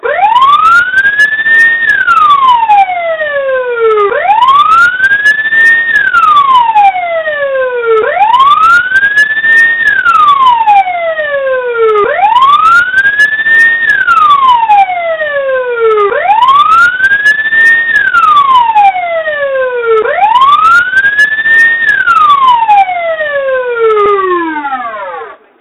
Звуки лежачего полицейского
На этой странице вы можете скачать и слушать звуки лежачего полицейского – реалистичные аудиофайлы, записанные с разных поверхностей и скоростей. Подборка включает резкие торможения, плавные переезды и другие варианты.